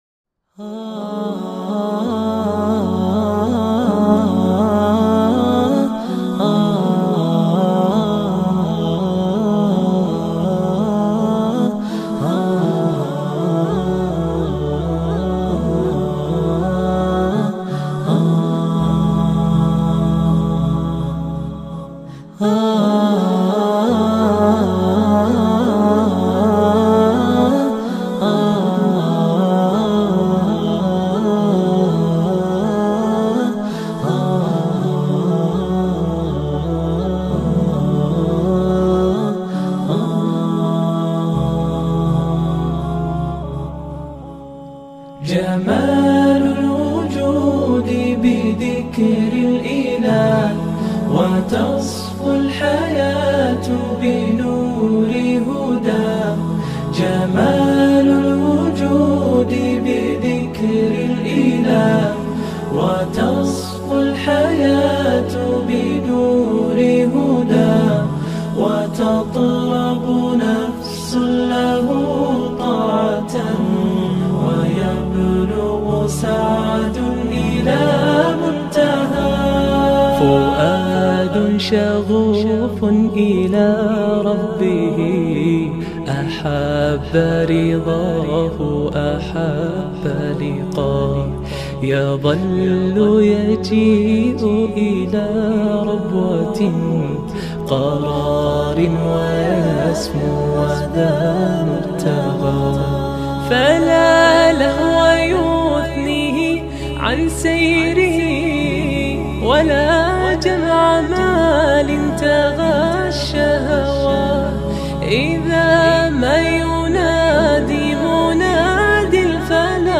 soulful recitation